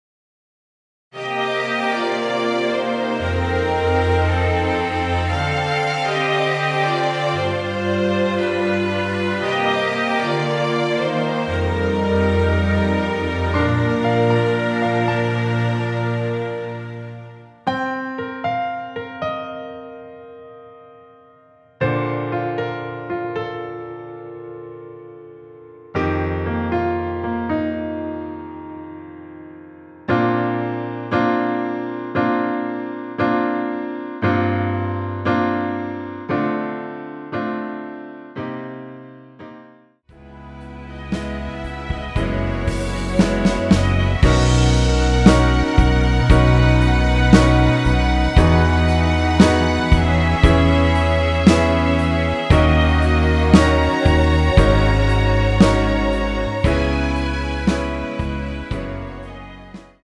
2절 부분은 삭제하고 어둠이 찾아 들어로 연결 됩니다.
Bb
앞부분30초, 뒷부분30초씩 편집해서 올려 드리고 있습니다.
중간에 음이 끈어지고 다시 나오는 이유는